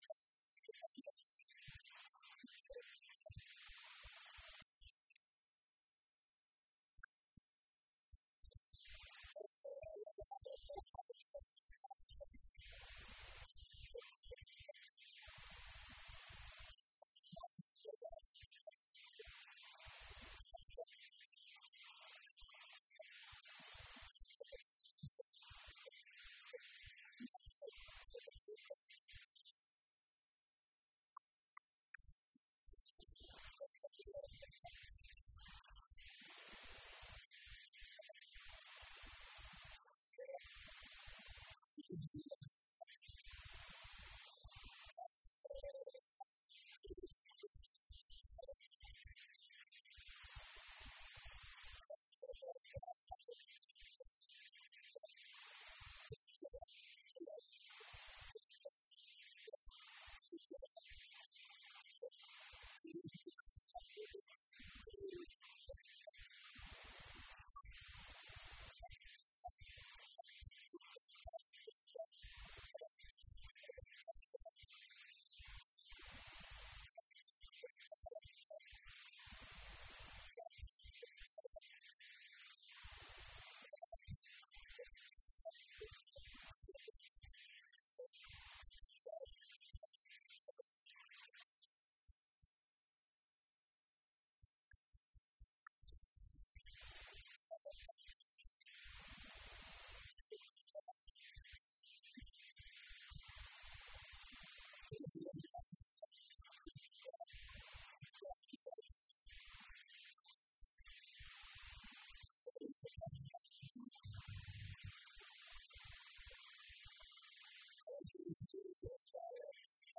R/wasaaraha Xukumadda Federaalka Soomaaliya mudane Xasan Cali Khayre ayaa hadalkaan ka sheegay xili uu shalay ka qeyb galayay Munaasabad ka dhacday Magaalada Muqdisho taasoo abaalmarin lagu Gudoonsiiyay mudane Xasan Cali Khayre.